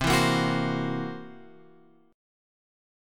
C 7th Flat 5th